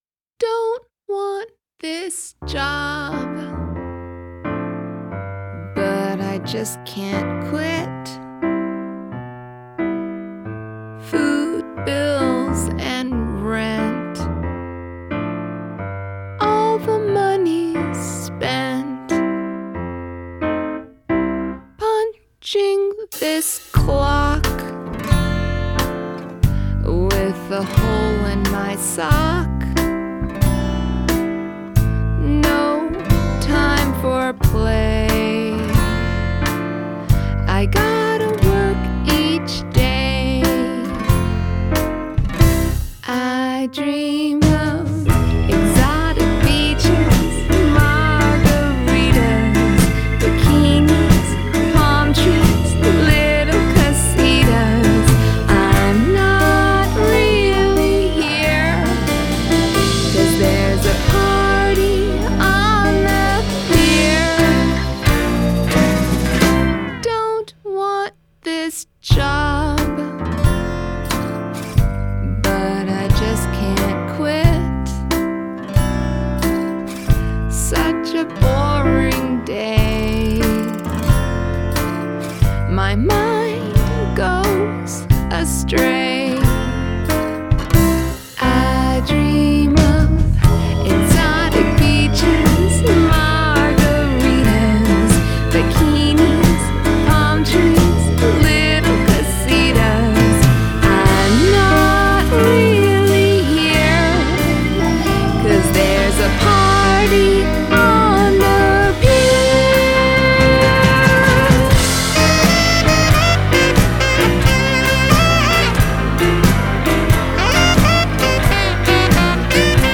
Adult Contemporary
Country , Indie Pop , Musical Theatre